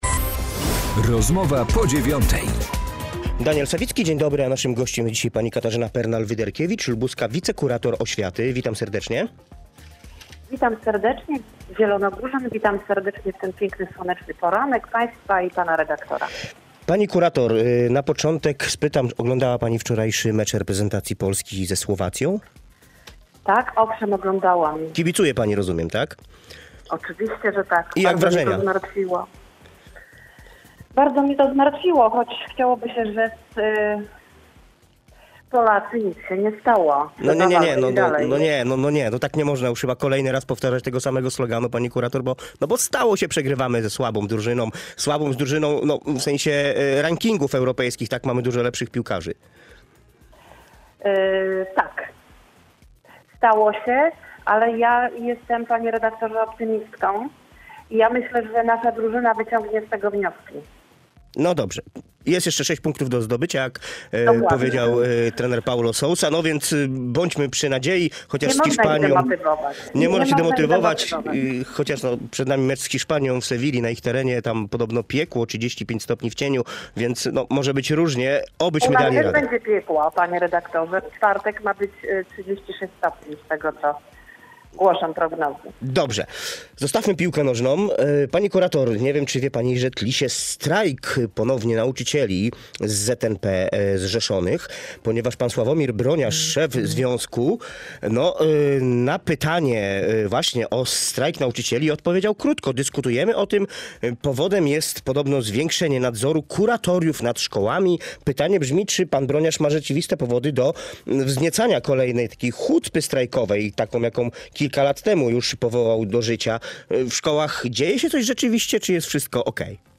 Katarzyna Pernal-Wyderkiewicz, lubuska wicekurator oświaty